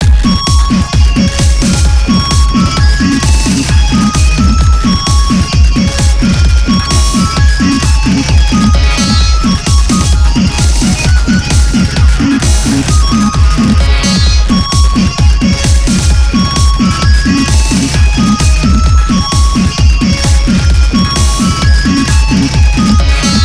holiday music interpretations